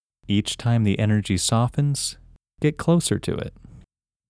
IN – First Way – English Male 15
IN-1-English-Male-15.mp3